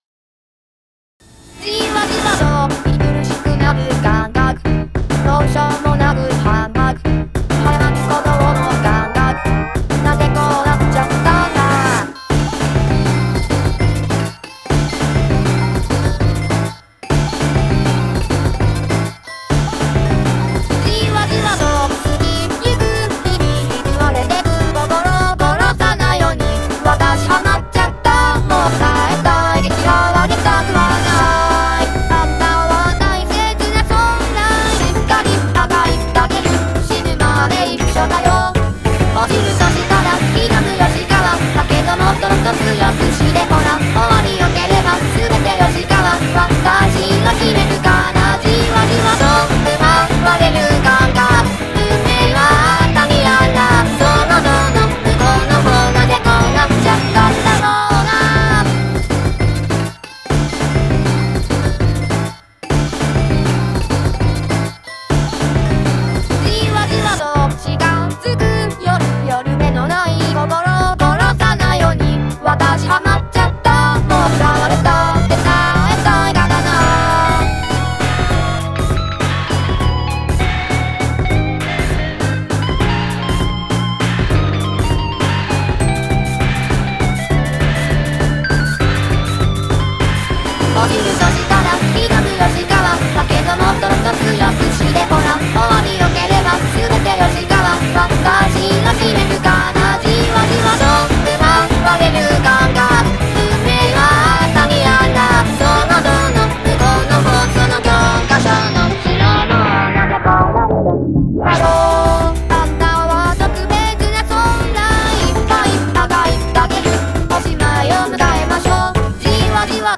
まだギターもベースを弾いてもらってないし、マスタリングは自分でやってます。